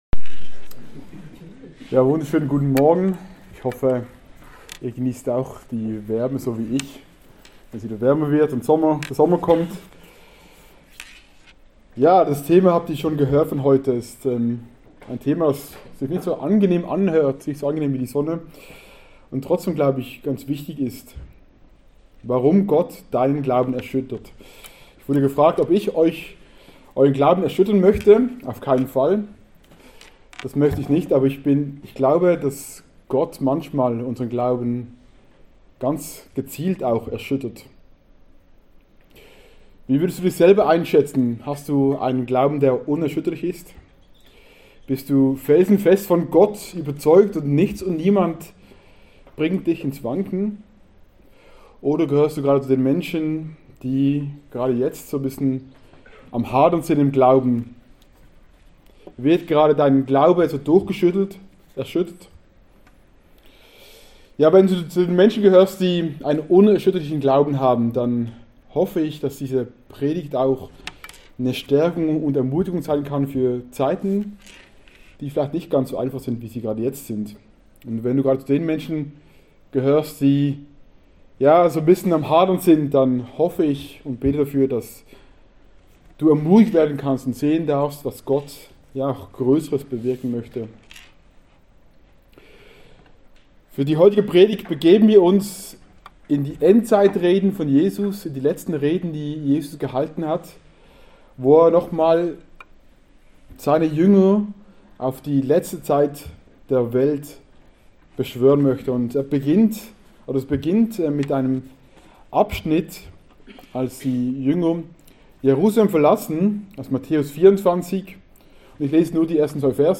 Series: Einzelne Predigten 2023